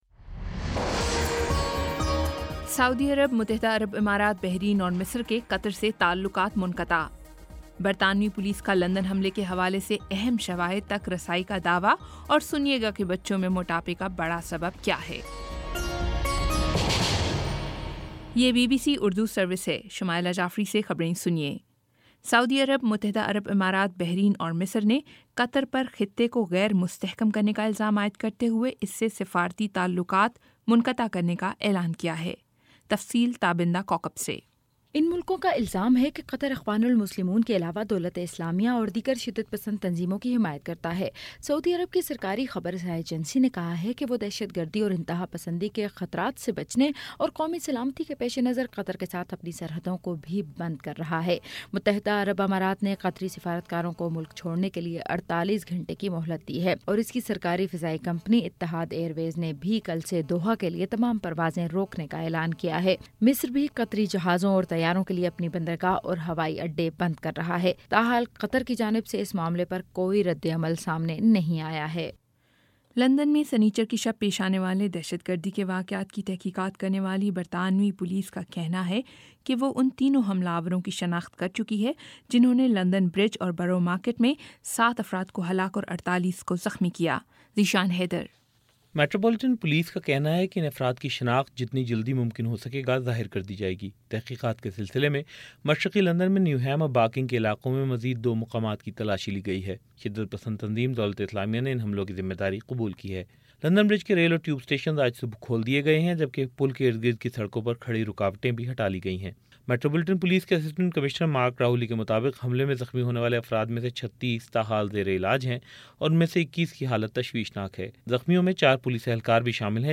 جون 05 : شام سات بجے کا نیوز بُلیٹن